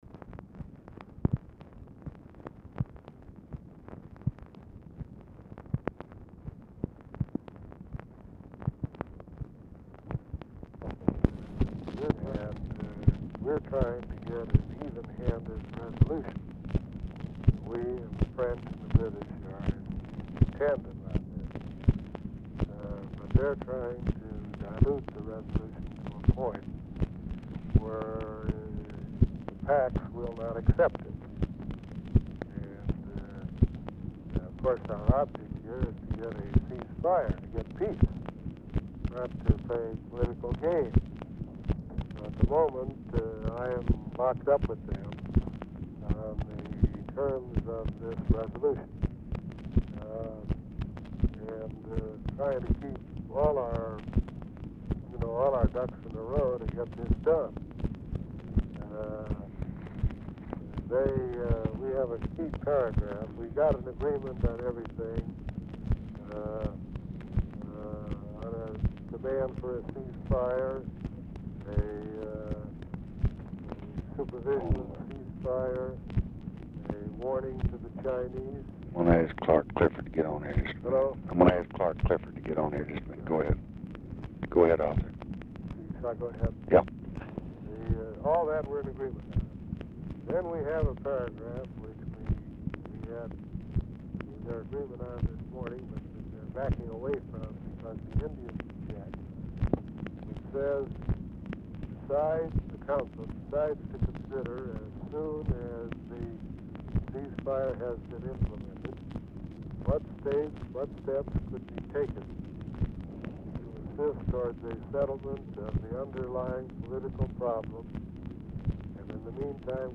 RECORDING STARTS AFTER CONVERSATION HAS BEGUN; GOLDBERG IS DIFFICULT TO HEAR;
Format Dictation belt
Location Of Speaker 1 Mansion, White House, Washington, DC
Specific Item Type Telephone conversation